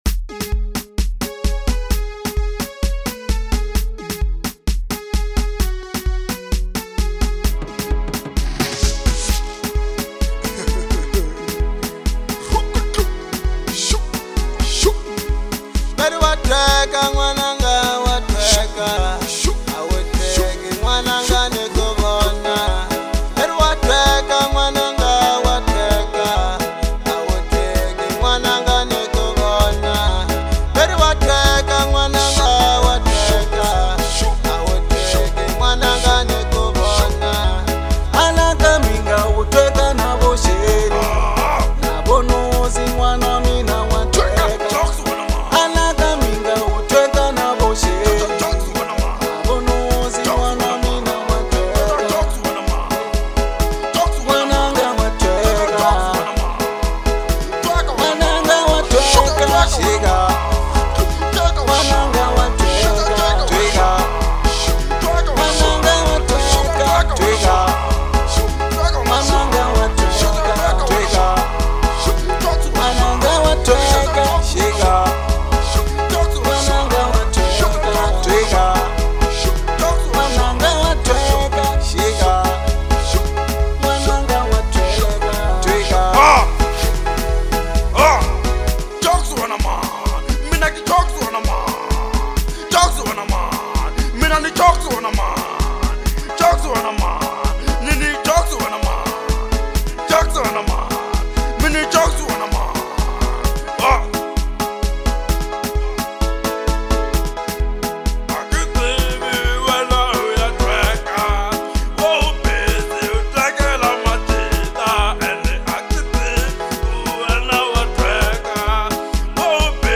03:14 Genre : African Disco Size